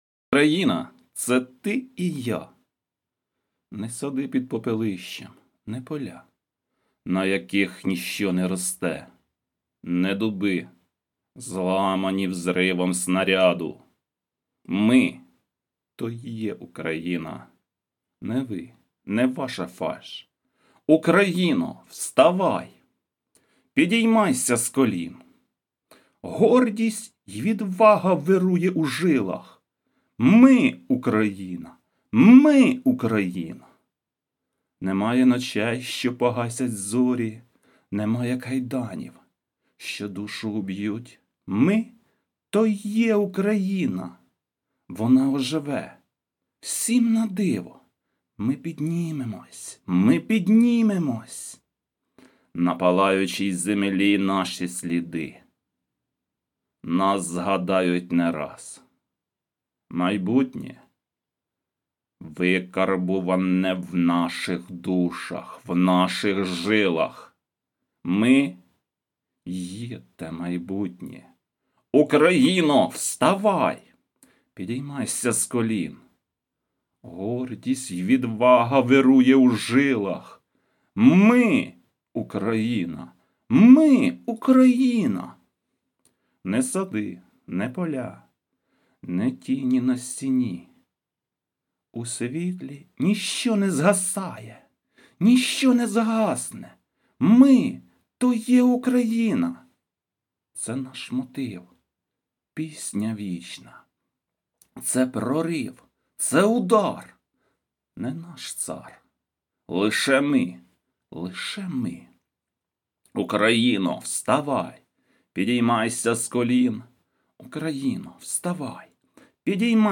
ВИД ТВОРУ: Вірш
дякую за відгук, продекламував, якщо є бажання можете послухати hi